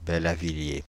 Bellavilliers (French pronunciation: [bɛlavilje]
Fr-Bellavilliers.ogg.mp3